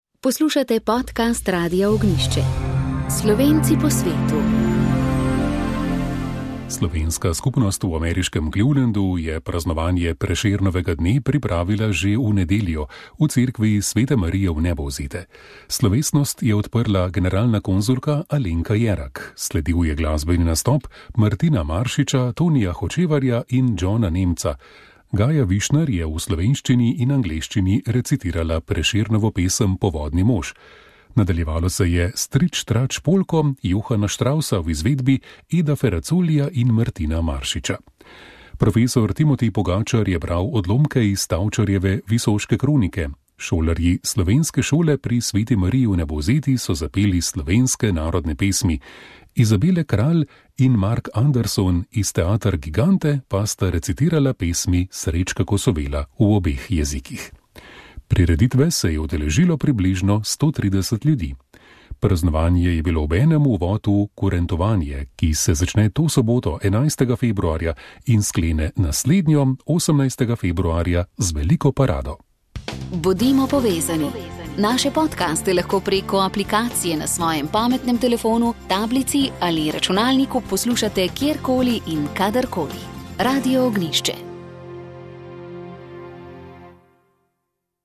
Slovenska skupnost v ameriškem Clevelandu je praznovanje Prešernovega dne pripravila že v nedeljo v cerkvi sv. Marije Vnebovzete.